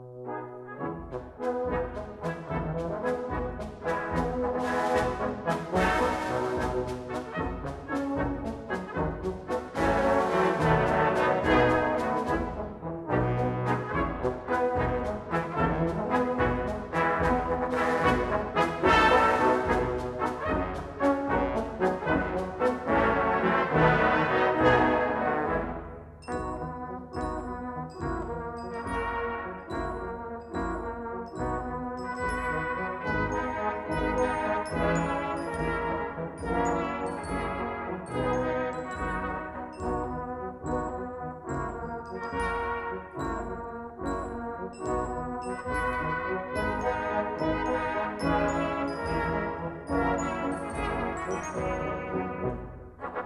1962 stereo recording